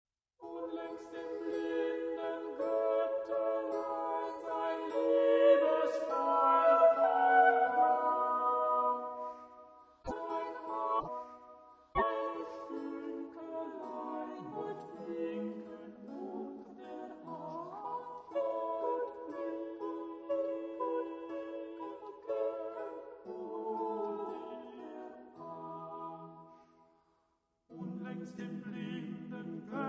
liuto